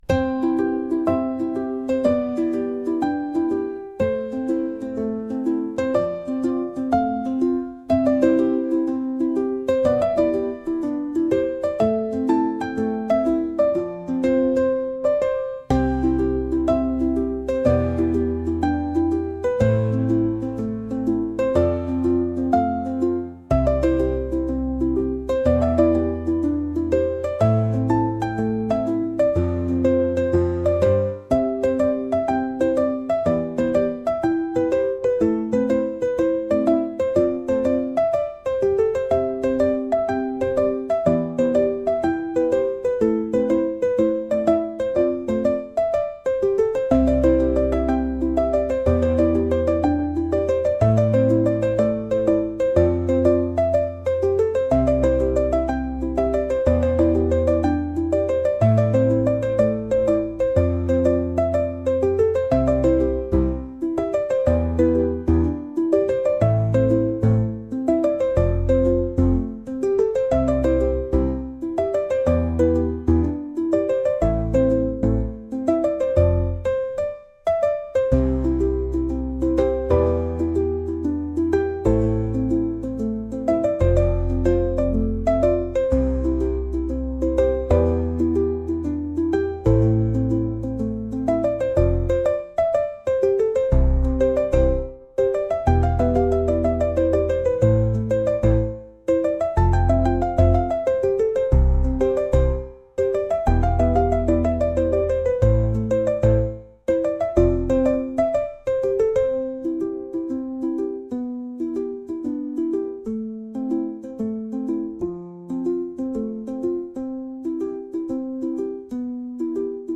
「明るい」